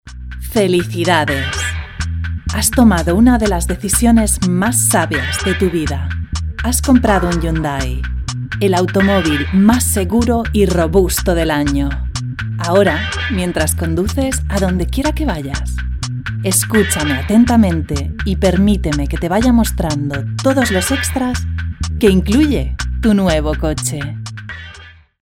VOICE Vocal Range: Medium Voice quality: smooth, soft, sensual, powerful, calm, deep, elegant, comforting, persuasive, convincing, informative, friendly, natural, warm, sincere, whispering, dynamique.
Her vocal style is versatile and perfect for corporate, commercial or narration work.
Sprechprobe: Industrie (Muttersprache):
- Fast turnaround 24/48h - In-house studio based in central Barcelona - Sennheiser MK4 microphone - Rycote Invision Popfilter - Beyerdynamic DT-770 Pro 250 - Focusrite Scarlett 2i2